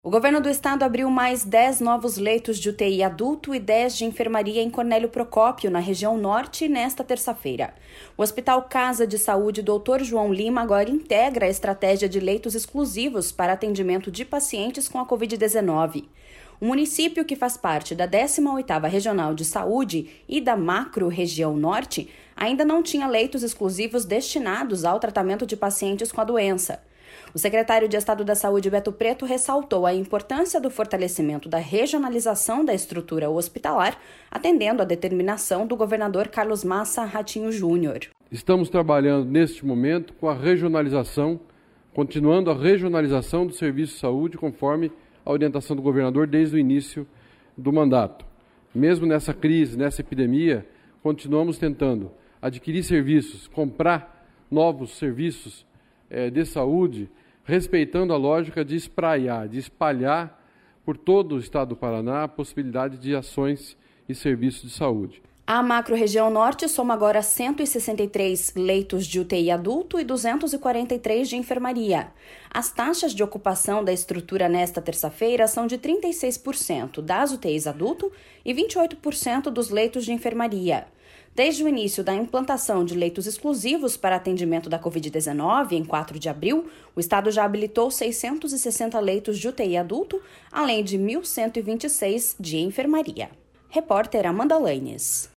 O secretário de Estado da Saúde, Beto Preto, ressaltou a importância do fortalecimento da regionalização da estrutura hospitalar, atendendo a determinação do governador Carlos Massa Ratinho Junior.// SONORA BETO PRETO.//